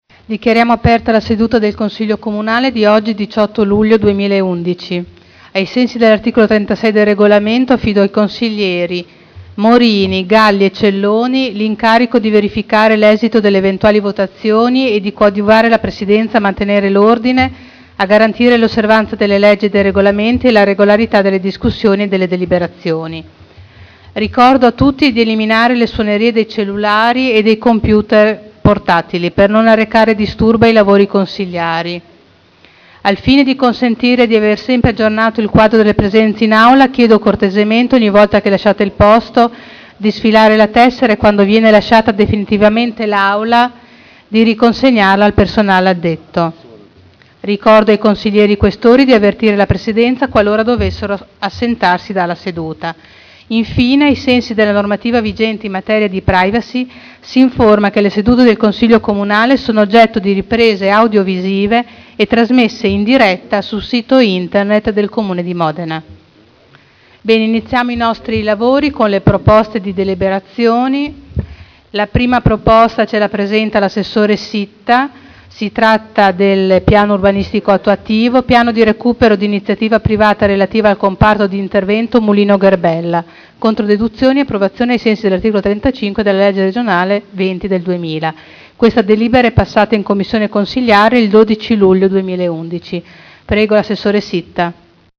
Seduta del 18/07/2011. Apertura del Consiglio Comunale